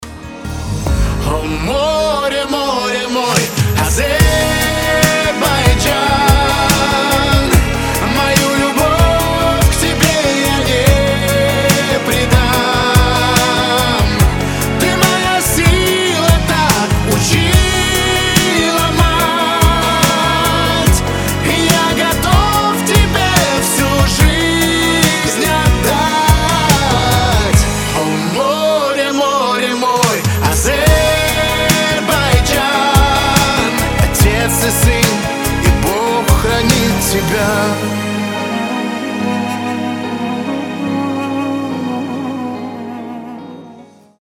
• Качество: 320, Stereo
поп
душевные
дуэт
ностальгия